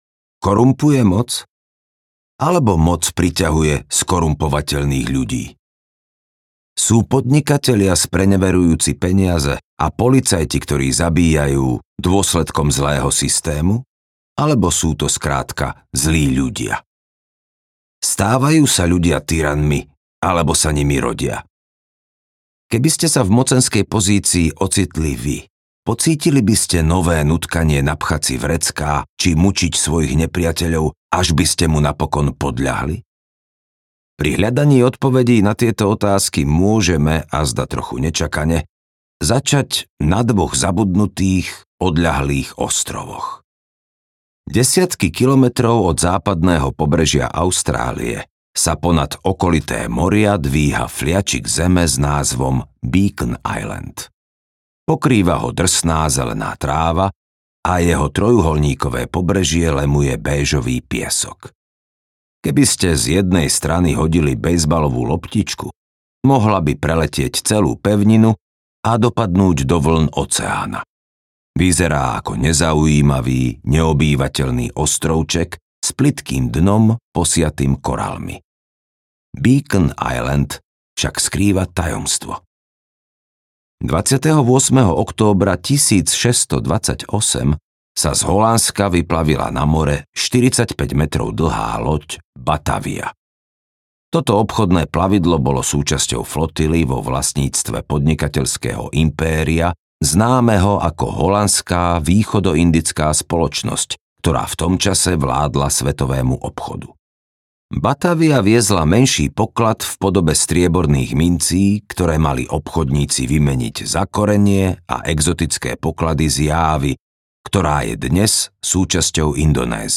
Moc audiokniha
Ukázka z knihy